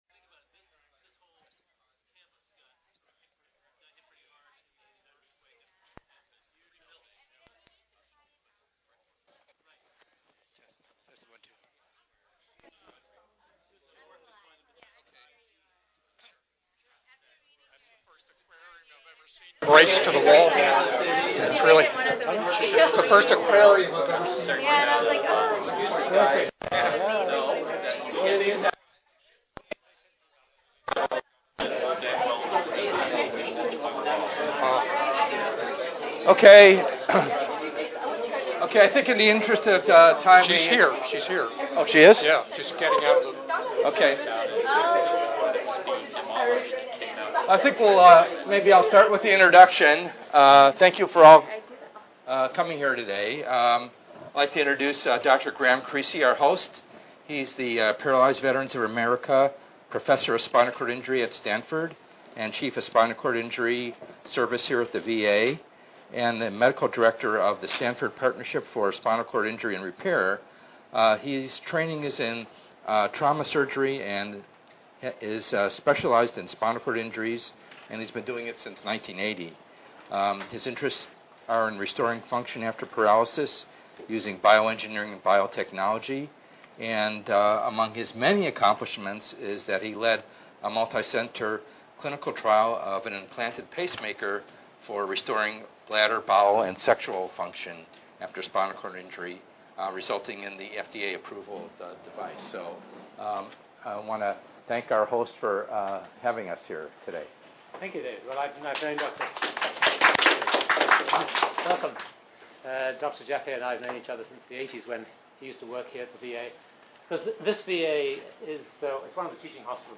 ENGR110/210: Perspectives in Assistive Technology - Lecture 09a
FRCSEd VA Palo Alto Health Care System This tour will be held at the VA Palo Alto Health Care System campus in the Spinal Cord Injury Service.